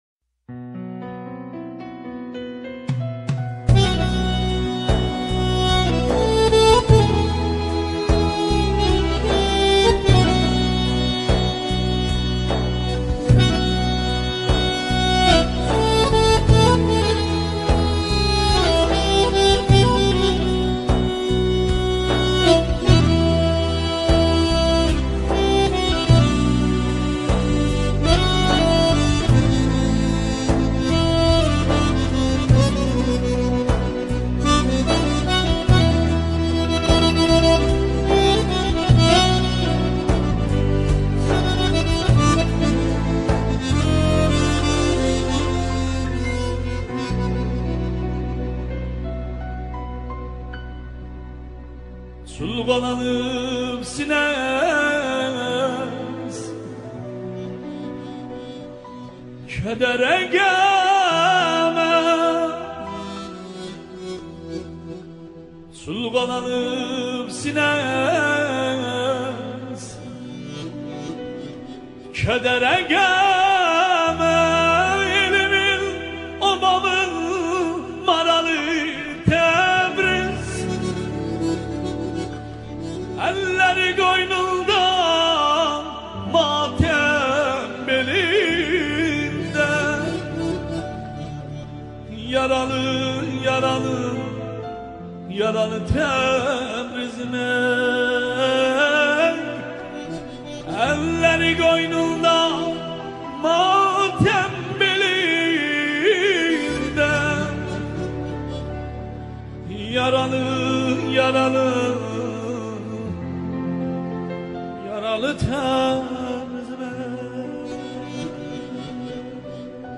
آهنگ ترکی
میکس تند